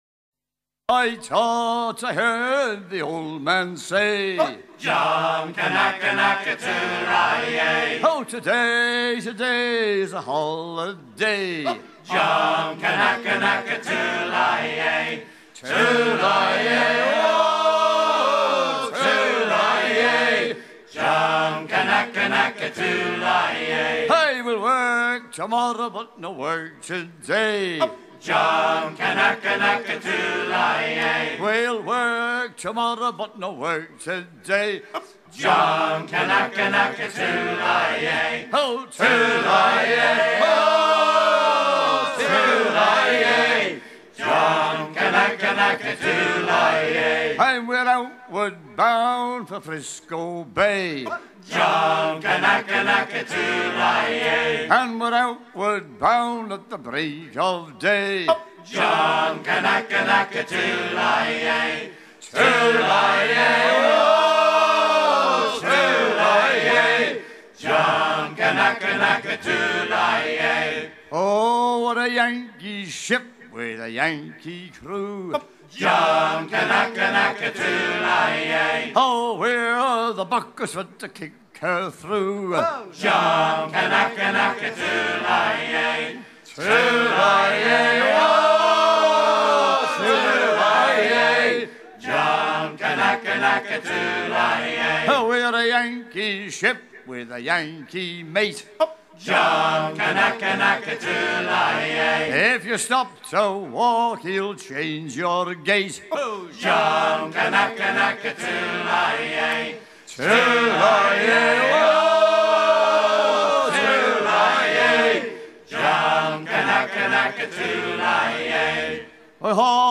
Chant enregistré lors de Dz 88.
Pièce musicale éditée